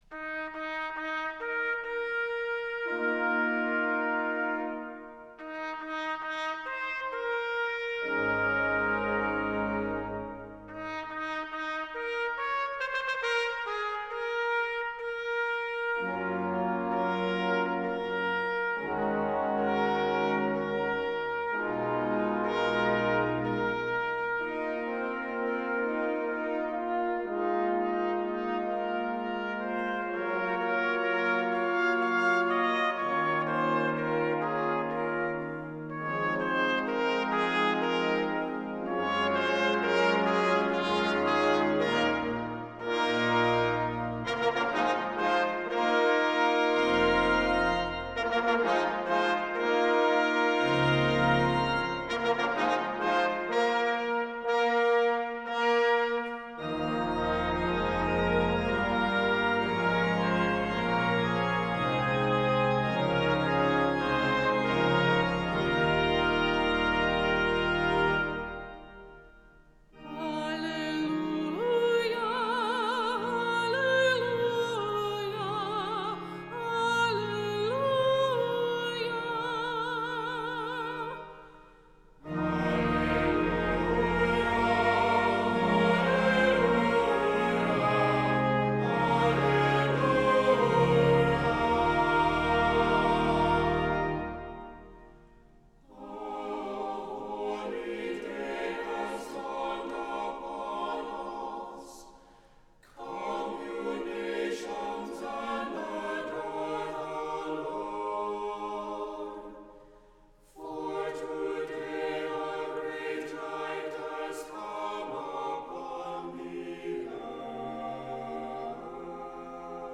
Voicing: "Unison with descant","Cantor","Assembly"